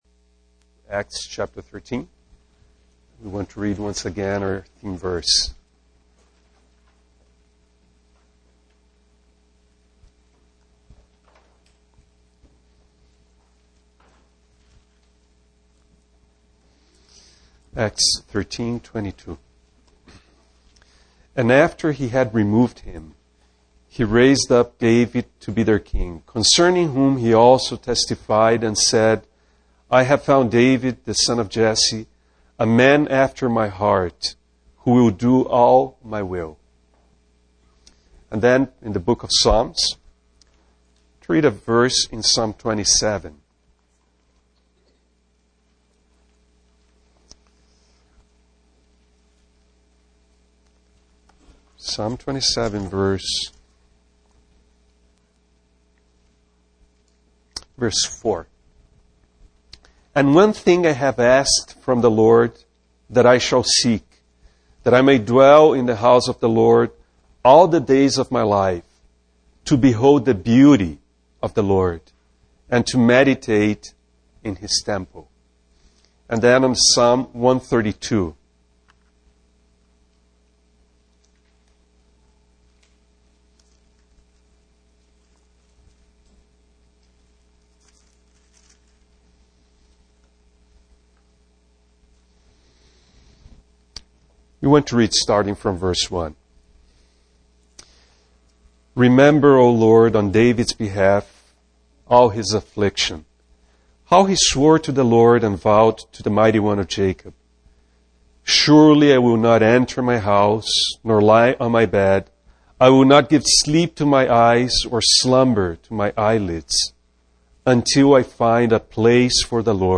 Richmond, Virginia, US We apologize for the poor quality audio